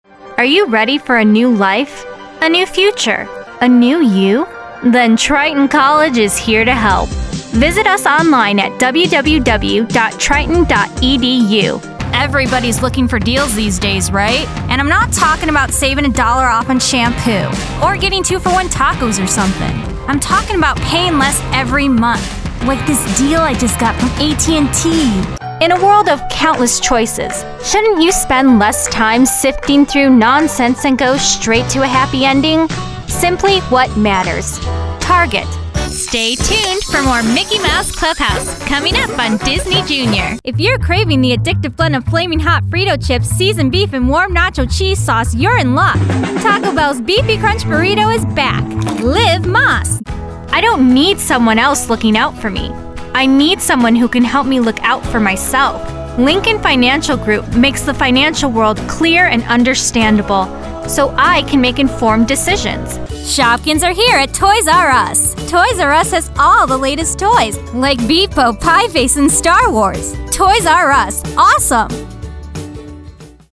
Award Winning Voice Actress
I have a broadcast quality home studio with Source Connect, Skype, and Zoom capabilities.